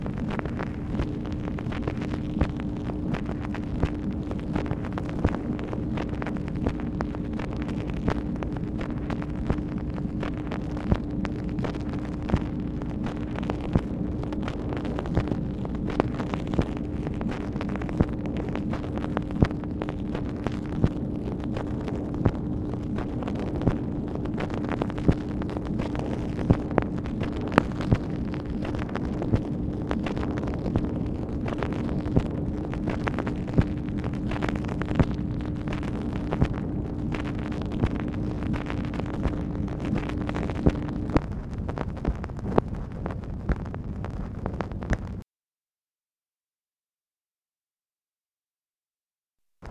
Conversation with MACHINE NOISE, October 11, 1964
Secret White House Tapes | Lyndon B. Johnson Presidency